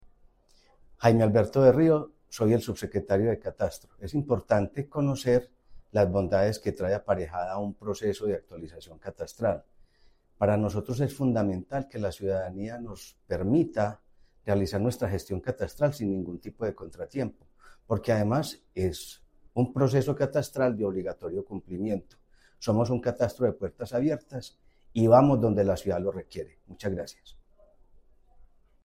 Palabras de Jaime Alberto Berrío Marín, subsecretario de Catastro En las primeras semanas de marzo se realizará la actualización catastral en tres comunas y un corregimiento de Medellín.